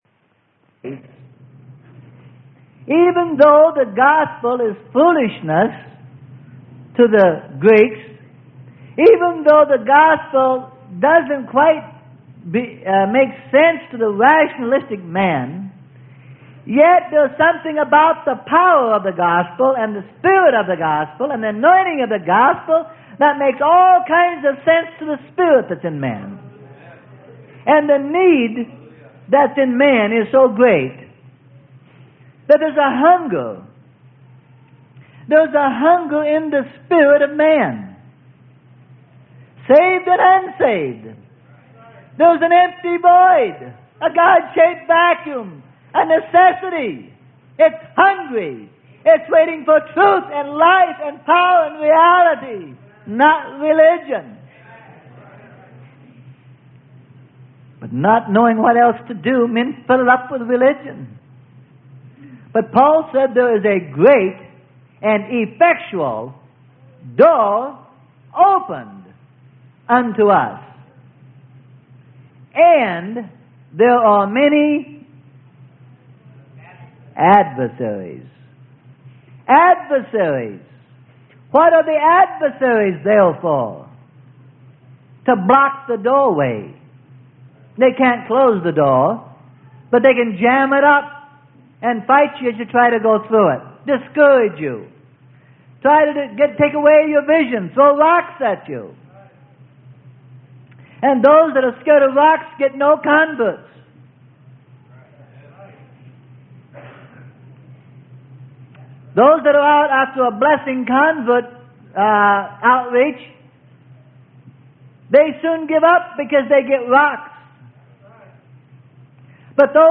Sermon: Compelling The Lost To Come In.